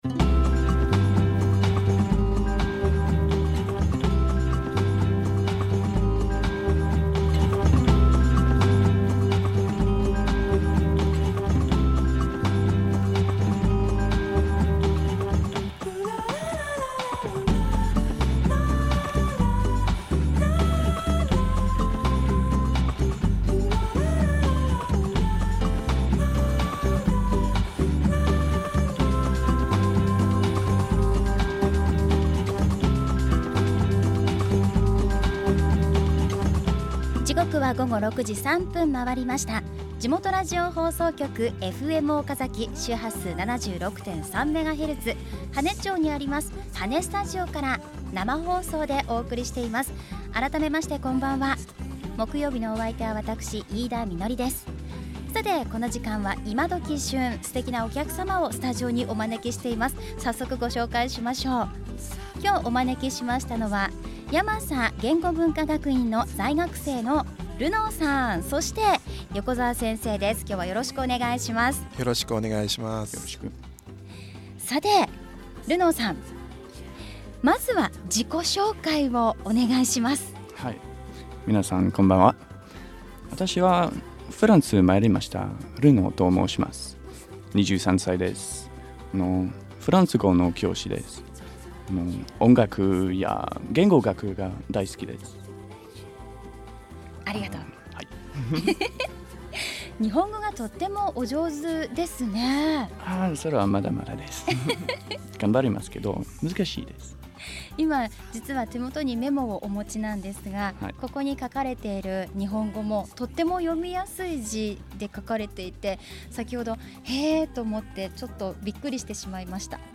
J’étais fatigué, les cours étaient finis, j’avais autre chose en tête, j’ai fait des erreurs bêtes de japonais, mais tout le monde m’a complimenté (mais bon, ils sont japonais).
Yamasaradio.mp3